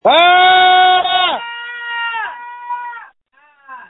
JUST SCREAM! Screams from December 1, 2020
• When you call, we record you making sounds. Hopefully screaming.